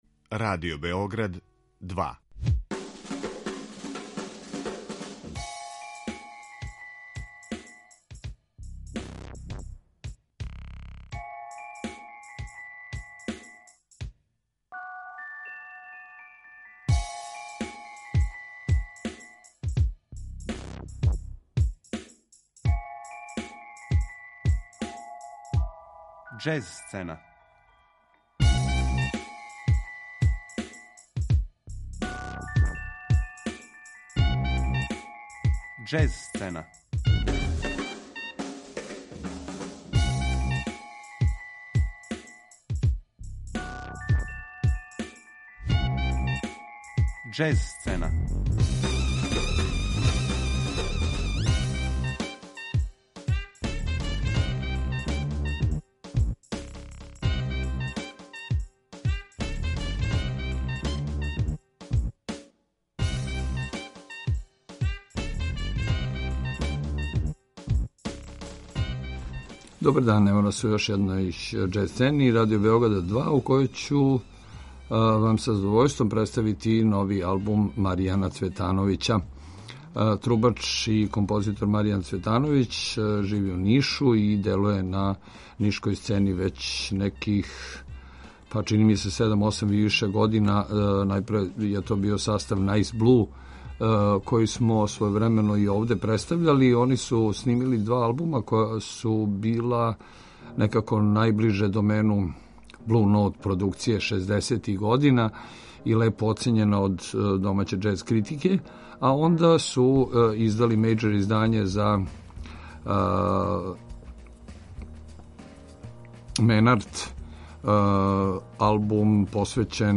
Актуелно остварење представља корак даље у каријери уметника, обликујући музику у водама савременог џез мејнстрима за састав квартета.